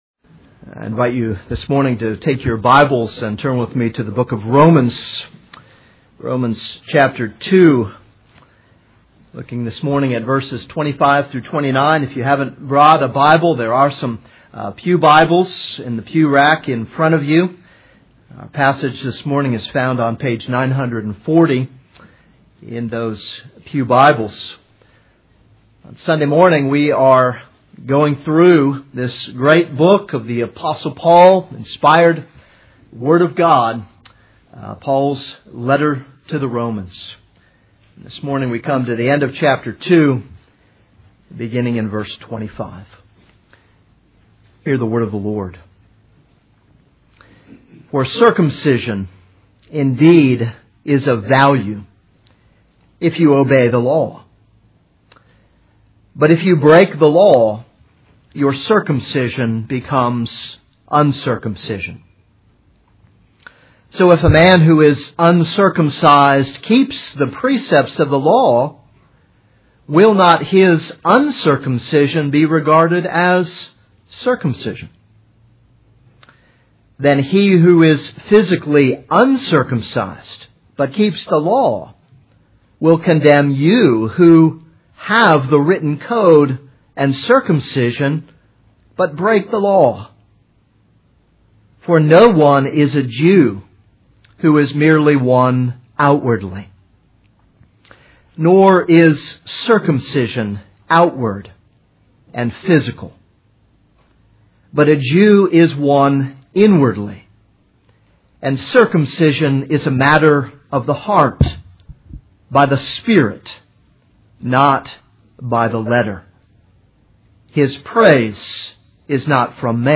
This is a sermon on Romans 2:25-29.